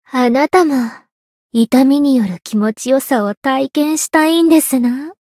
灵魂潮汐-安德莉亚-闲聊-不开心.ogg